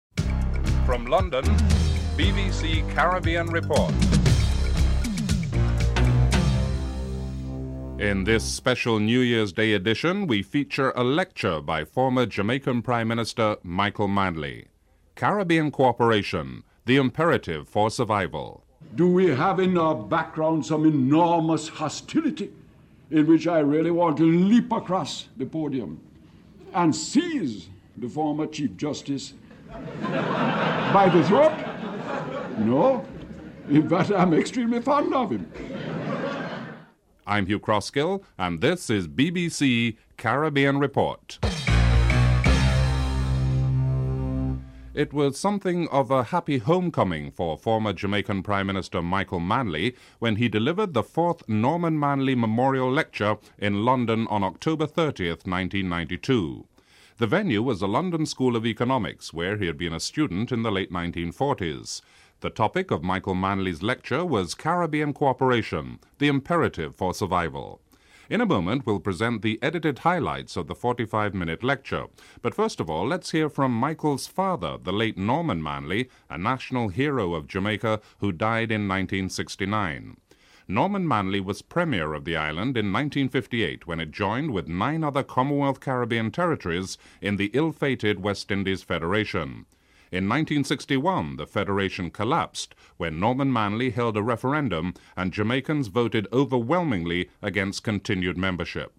The edited highlights on the 45 minute 4th Norman Washington Manley Memorial lecture delivered on October 30th,1992 at the London School of Economics where he was a student in the late 1940's.
1. Headlines: Special New Year's Day edition - a lecture by former Jamaican Prime Minister Michael Manley entitled: Caribbean Cooperation: the imperative for survival - 00:00-00:43min.